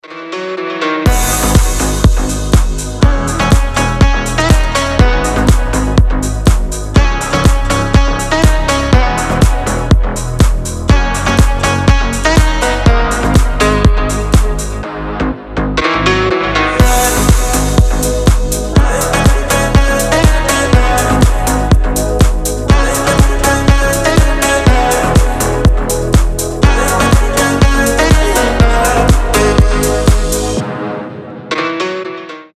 • Качество: 320, Stereo
deep house
восточные
Хорошая музыка для любителей Востока